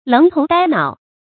楞頭呆腦 注音： ㄌㄥˊ ㄊㄡˊ ㄉㄞ ㄣㄠˇ 讀音讀法： 意思解釋： 見「楞頭楞腦」。